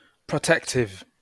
wymowa:
IPA/prɛtɛktɪv/ lub /proʊtɛktɪv/